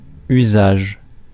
Ääntäminen
IPA: [y.zaʒ]